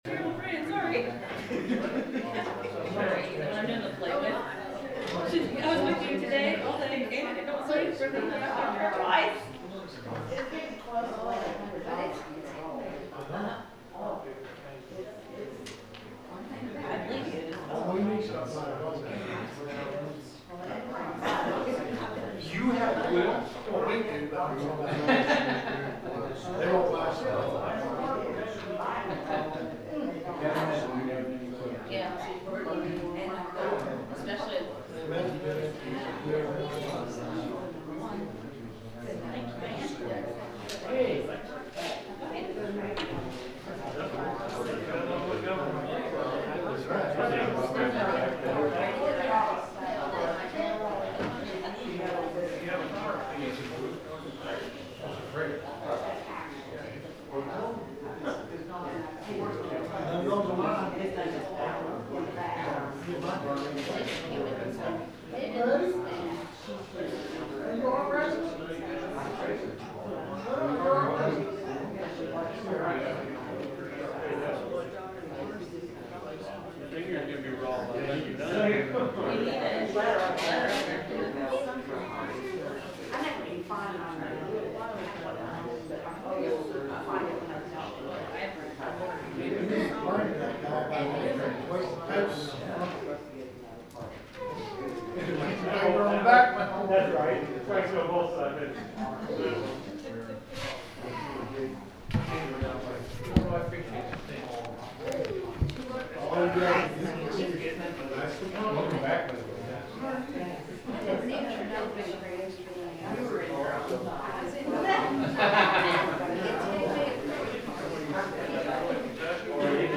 The sermon is from our live stream on 2/4/2026